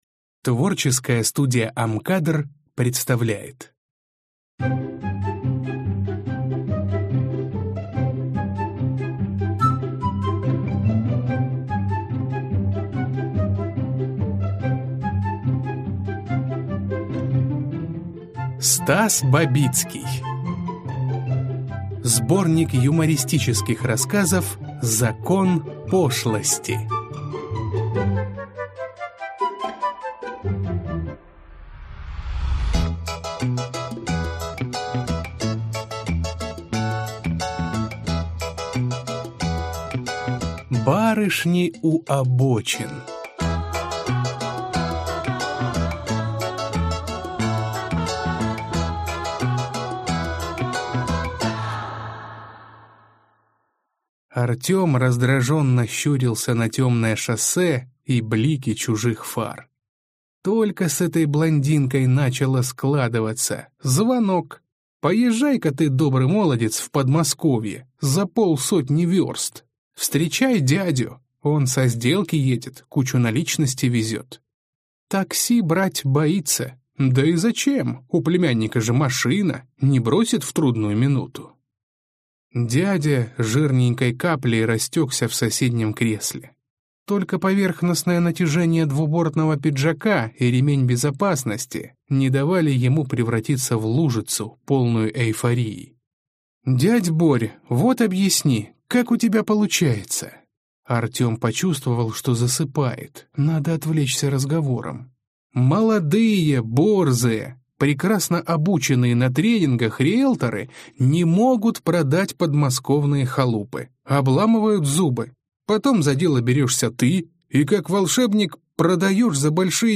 Аудиокнига Закон пошлости | Библиотека аудиокниг